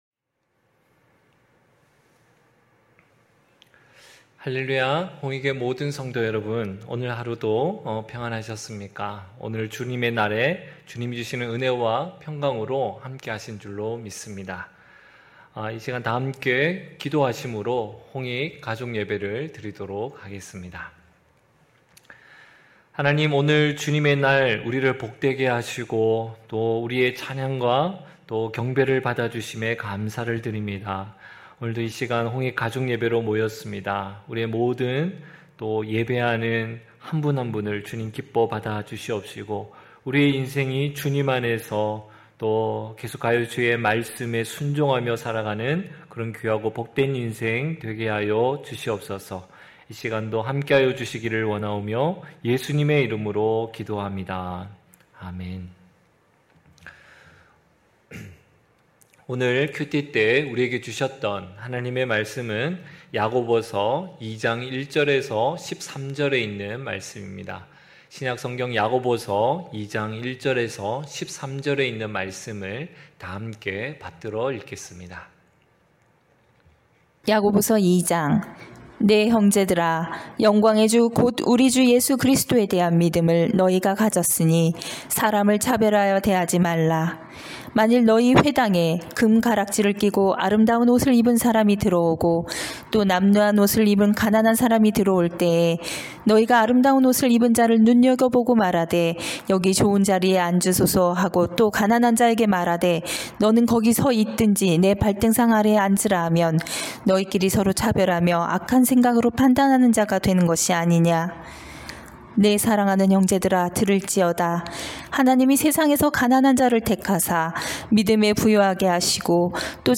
9시홍익가족예배(7월4일).mp3